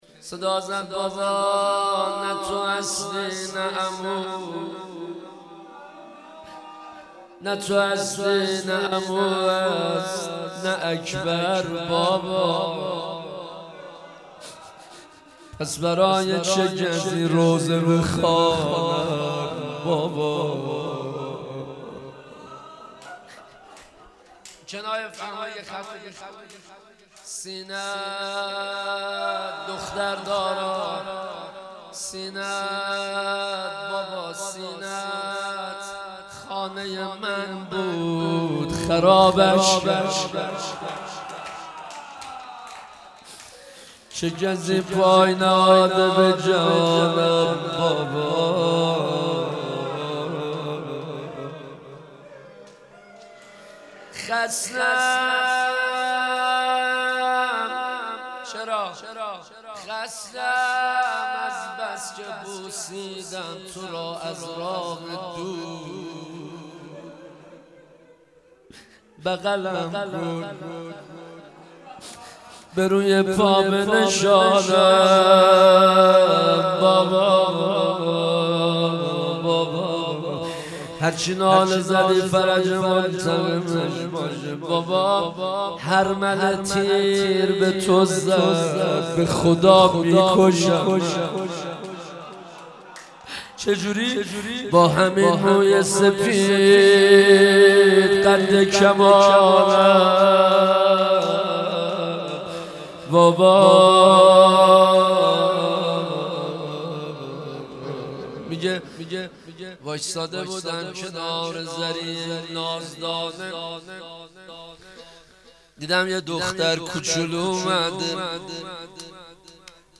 شب شهادت حضرت رقیه (س) جمعه 12 مهر 1398 مکتب الرضا علیه السلام تهران